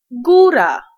Ääntäminen
IPA : /ˈtɒp/ IPA : [tʰɒp] GenAm: IPA : /ˈtɑp/ IPA : [tʰɑp]